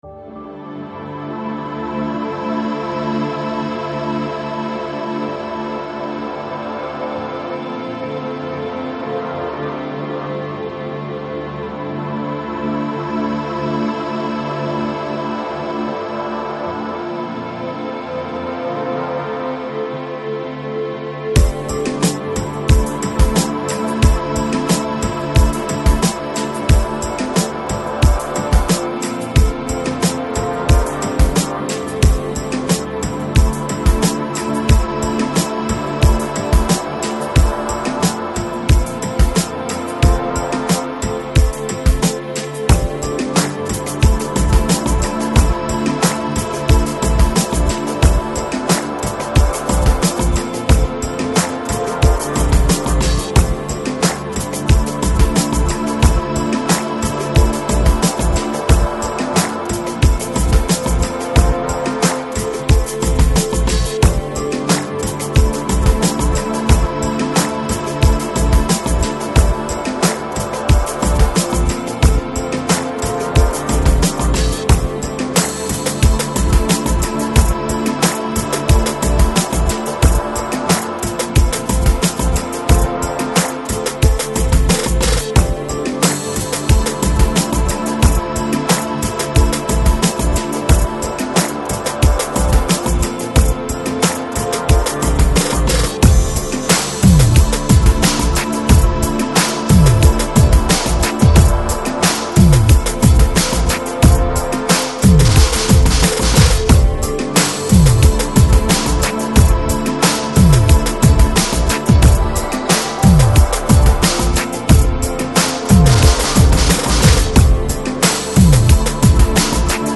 Electronic, Lounge, Chill Out, Downtempo, Balearic